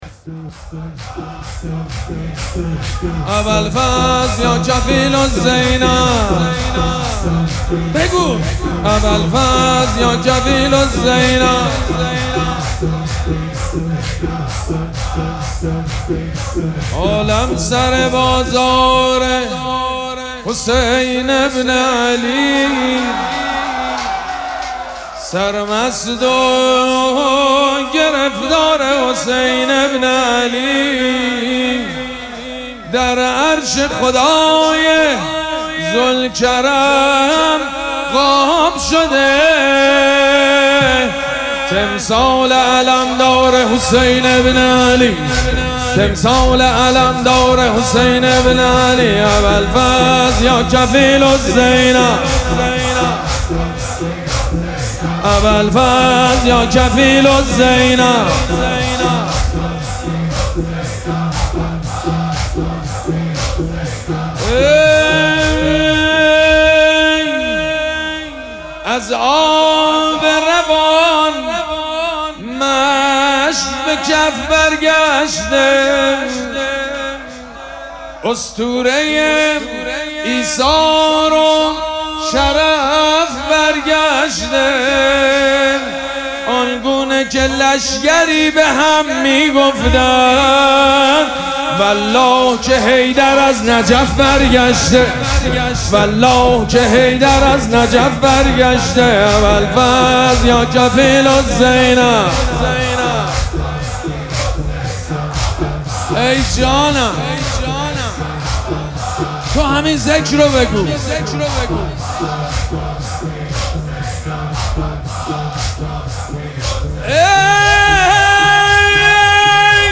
مراسم عزاداری وفات حضرت ام‌البنین سلام‌الله‌علیها
مداح
حاج سید مجید بنی فاطمه